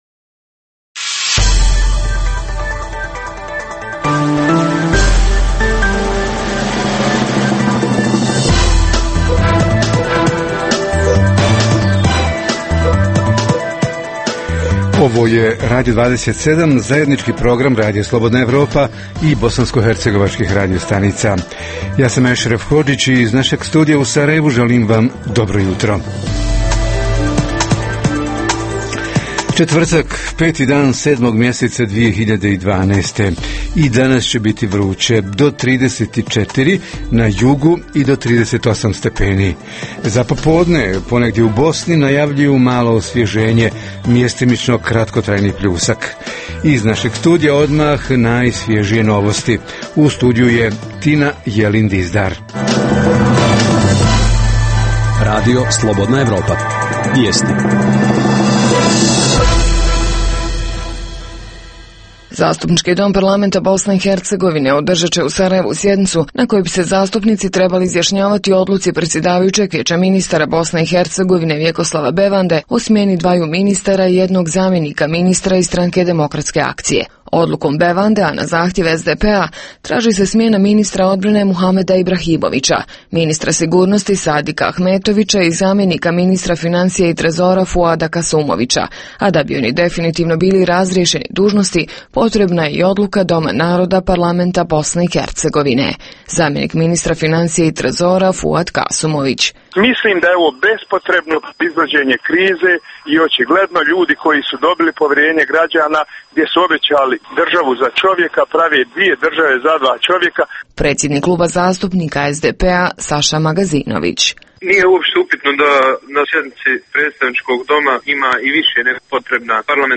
Jutarnji program za BiH emituje se uživo. Tema ovog jutra su ljetne vreline i ishrana – ko, kako i koliko često kontroliše zdravstvenu ispravnost hrane u trgovinama i ugostiteljskim objektima?
Redovni sadržaji jutarnjeg programa za BiH su i vijesti i muzika.